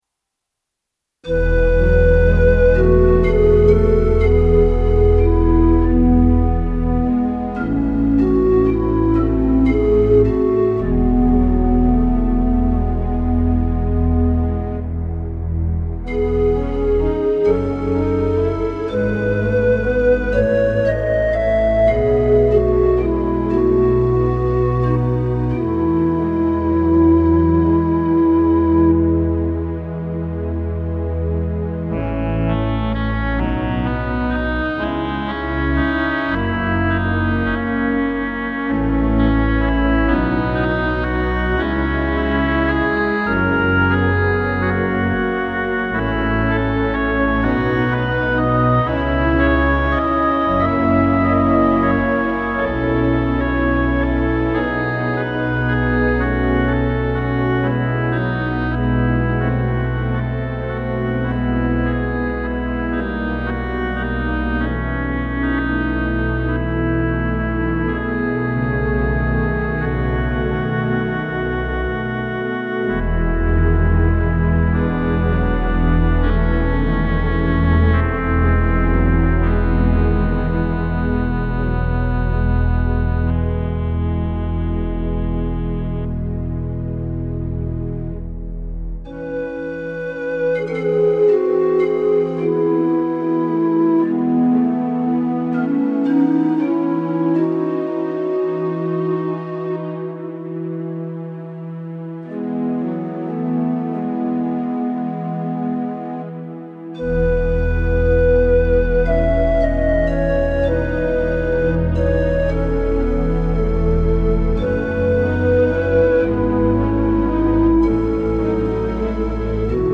Organ Interludes Audio Gallery